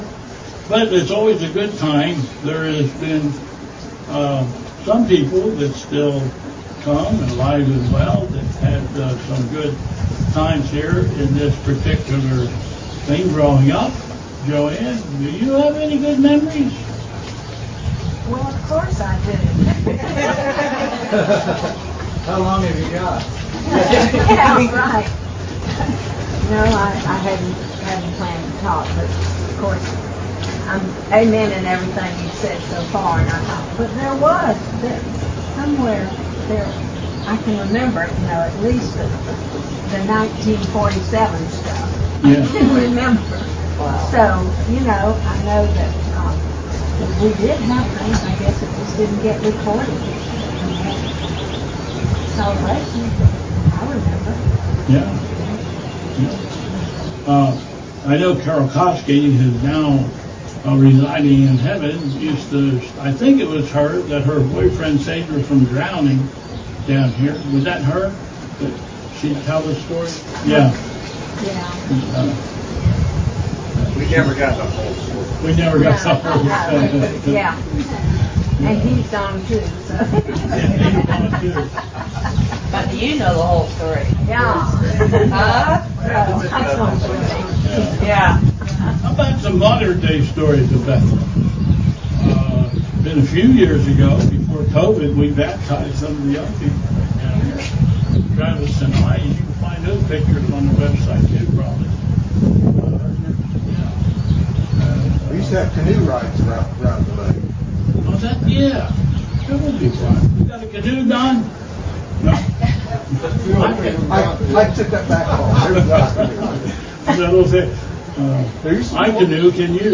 All of the recordings on this page come from videos taken at the service which were too large to put online. As such, they are not very good...with the noise of unshielded wind.
Storytime